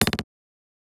Destroy - ElementsPerc.wav